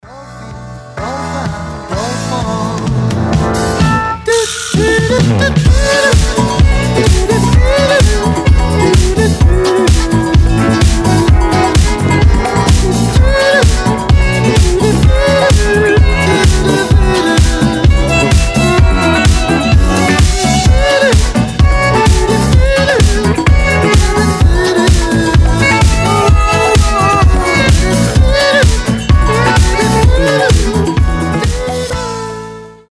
• Качество: 320, Stereo
позитивные
веселые
смешные
Фанк